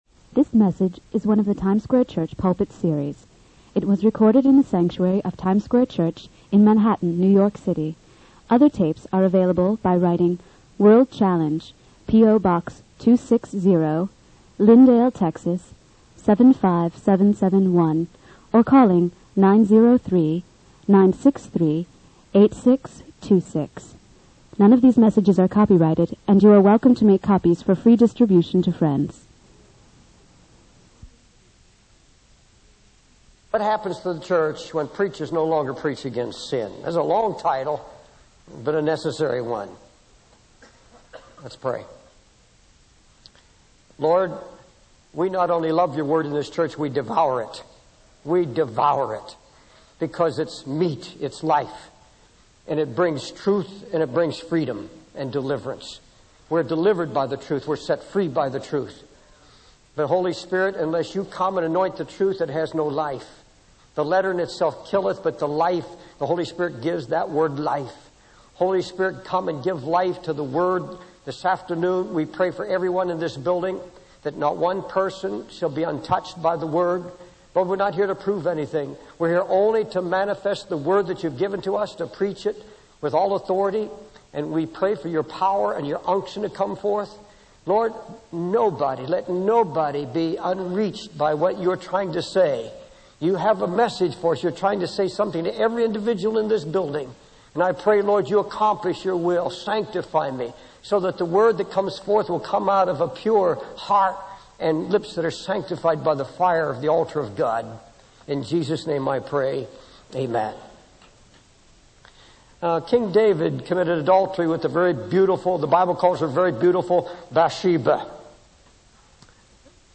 It was recorded in the sanctuary of Times Square Church in Manhattan, New York City.